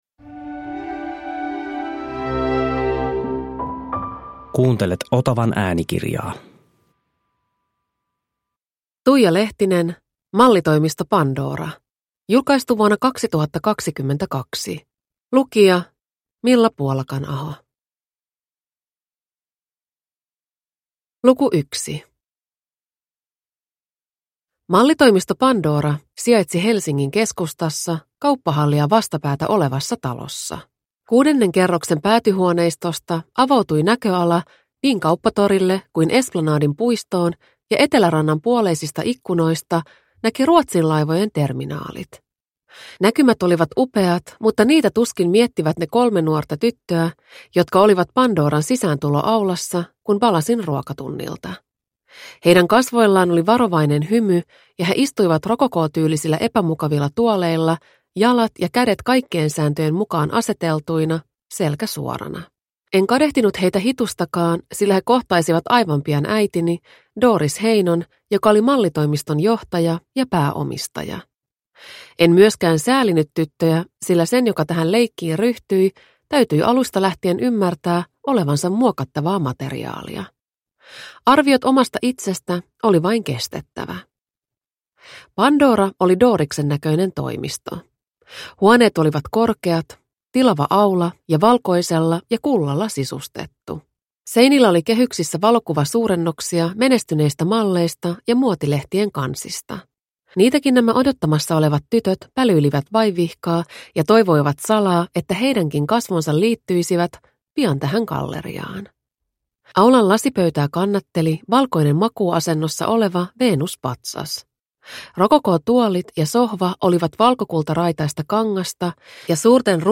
Mallitoimisto Pandora – Ljudbok – Laddas ner